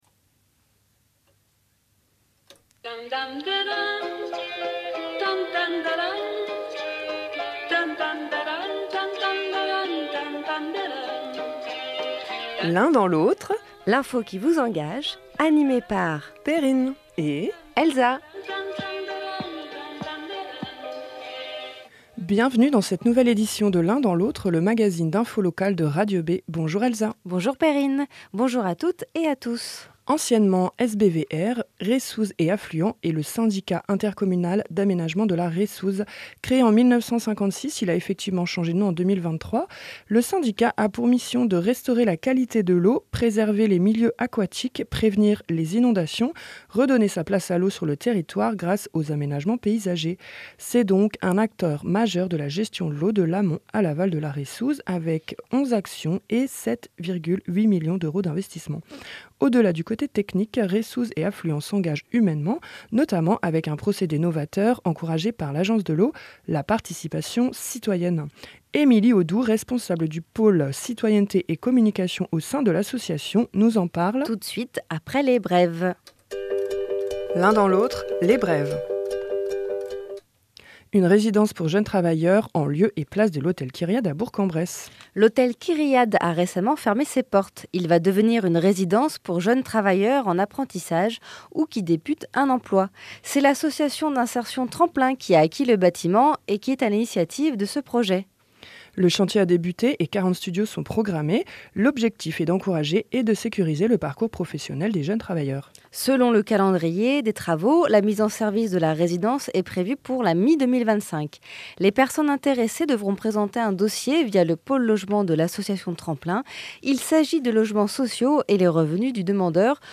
Cette semaine, dans votre agazine d'infos locales, on vous parle de :